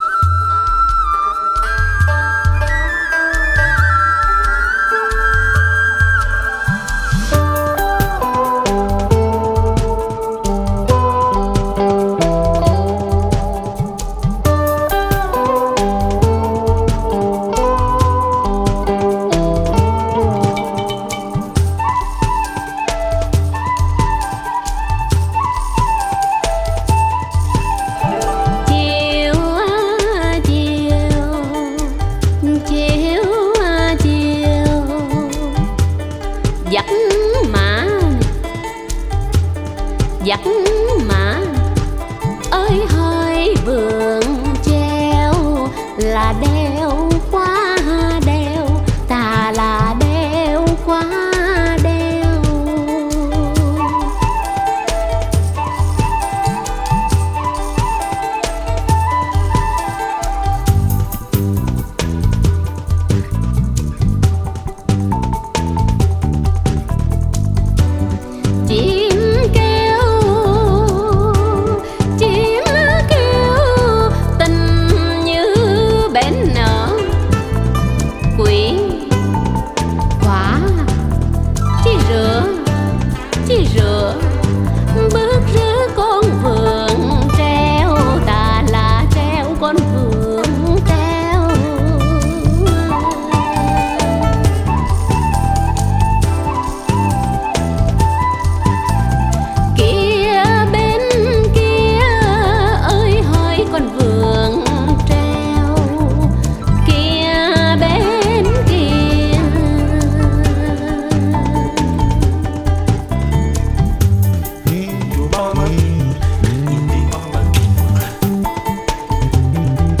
BPM135
MP3 QualityMusic Cut